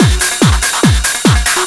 DS 144-BPM B1.wav